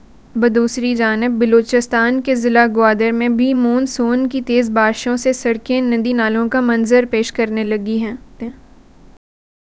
deepfake_detection_dataset_urdu / Spoofed_TTS /Speaker_05 /265.wav